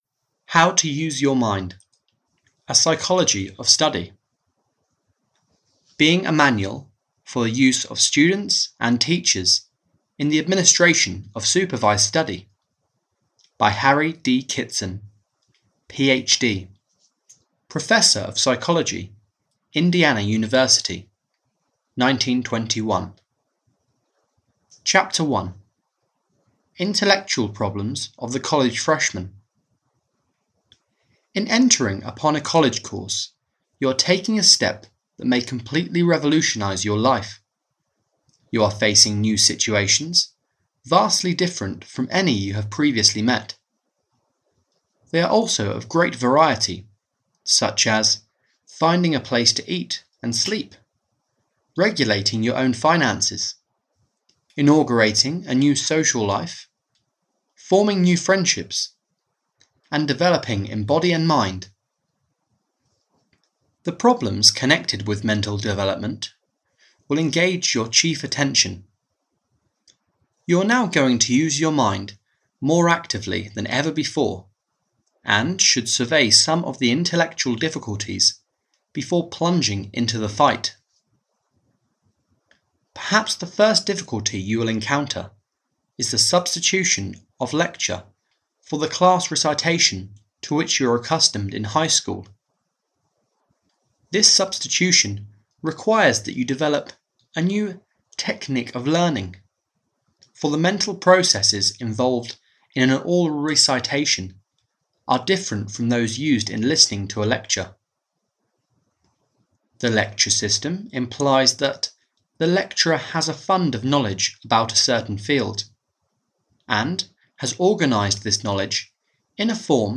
How to Study – A Psychology Of Study (EN) audiokniha
Ukázka z knihy